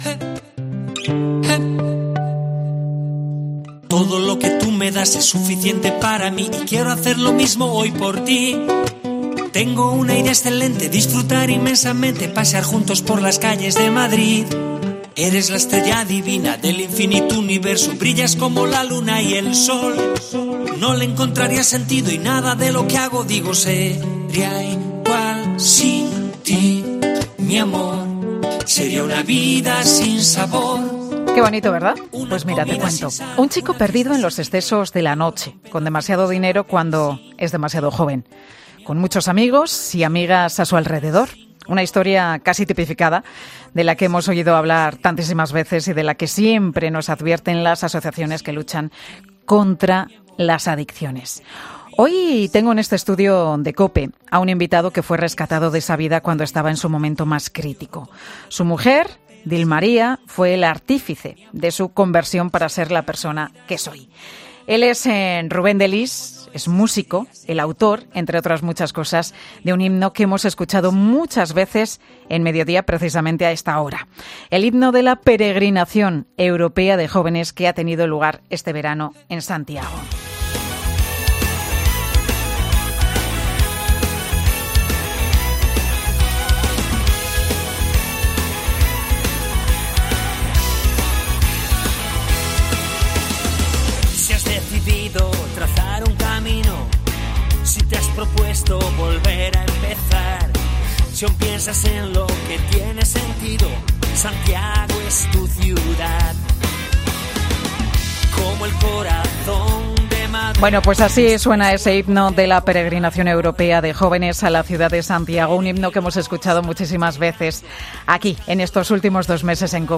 Hoy en el estudio de 'Mediodía COPE' Pilar García Muñiz ha recibido a un invitado que fue rescatado de esa vida cuando estaba en su momento más crítico.